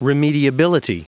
Prononciation du mot remediability en anglais (fichier audio)
Prononciation du mot : remediability